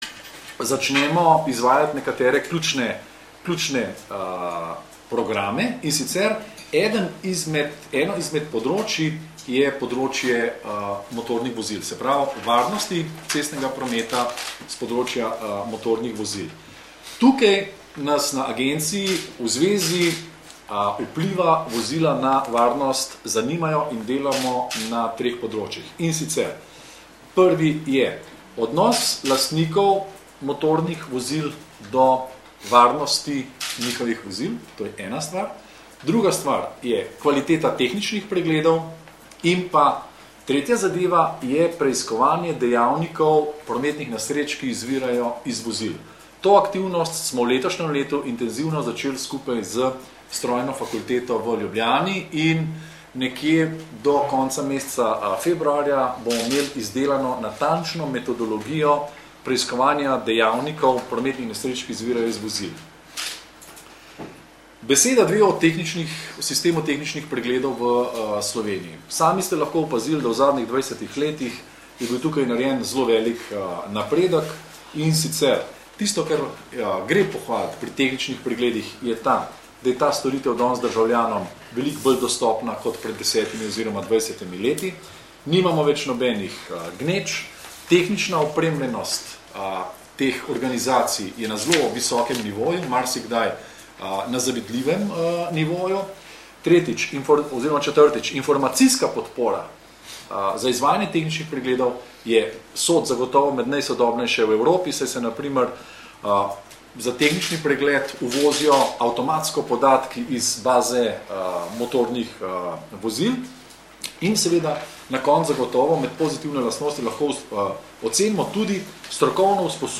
Informacija z novinarske konference – ugotovitve na tehničnih pregledih